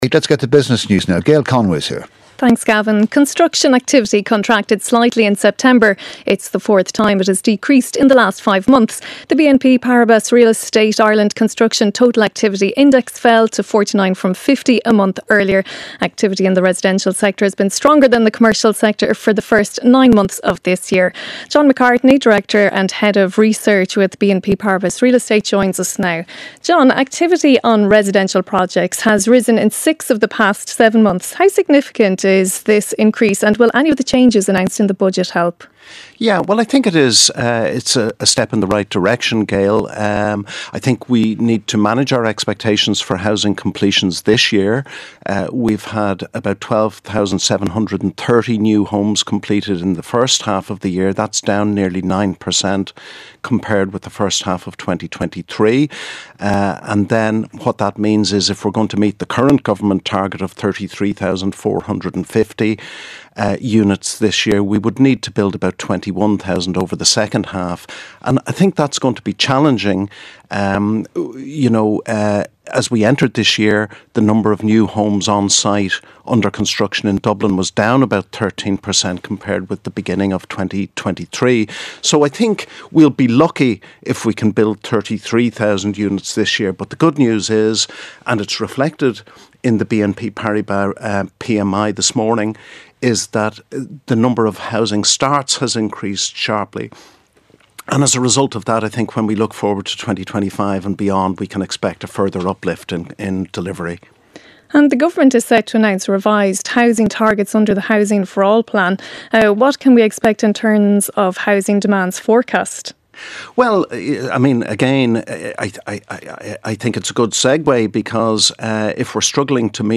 7:35am Sports News - 14.10.2024